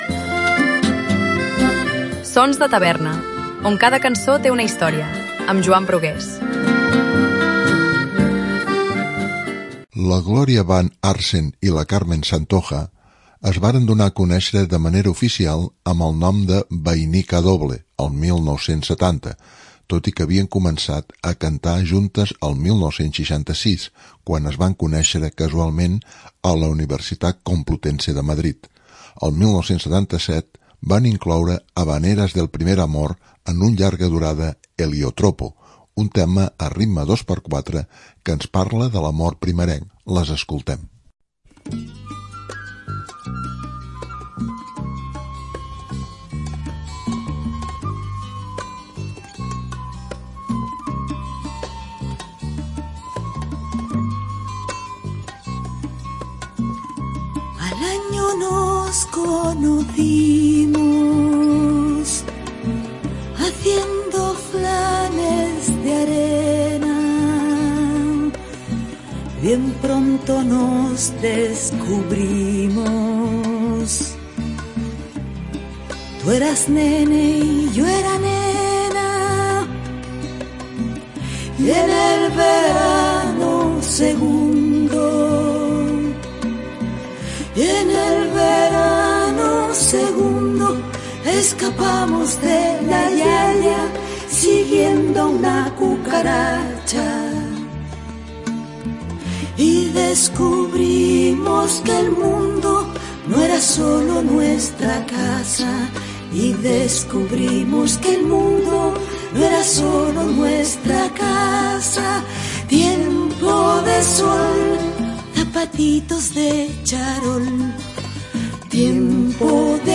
a ritme de 2x4